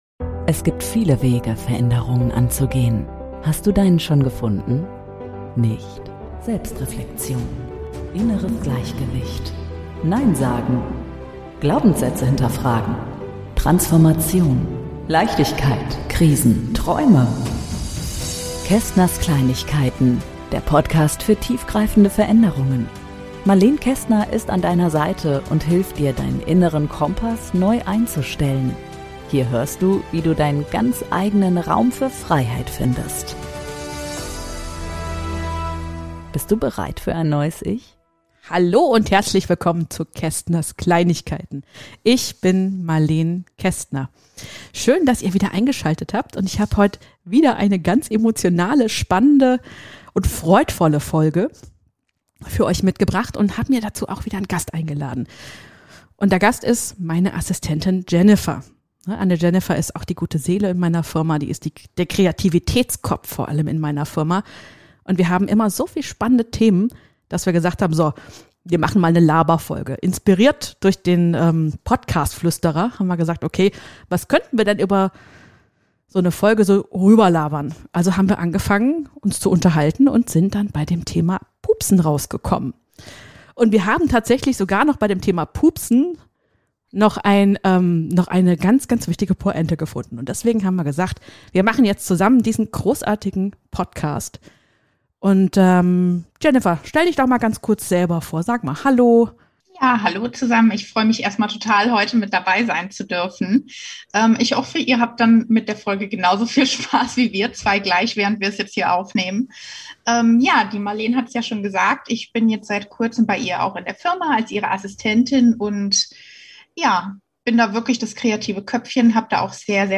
#009 Das Pupshandtuch - Ein Interview zum Thema Selbstwert